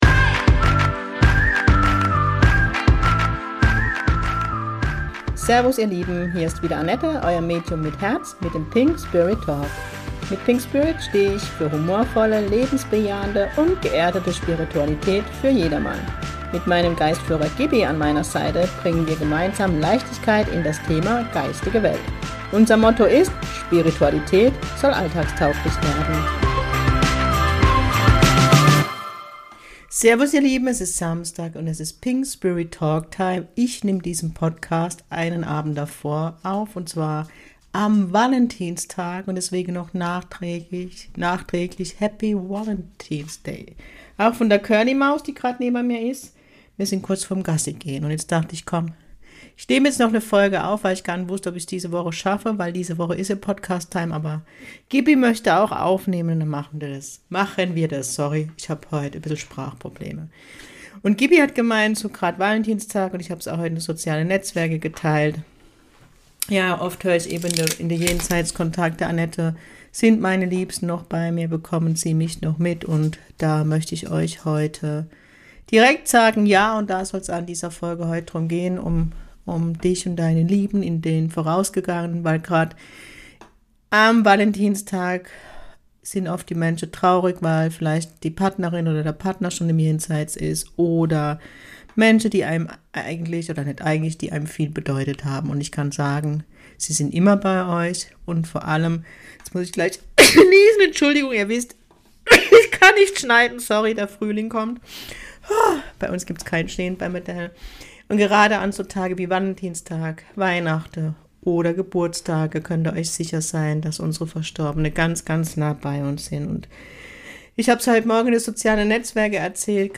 Und das alles mit ganz viel Leichtigkeit und meinem Kurpfälzer Charme.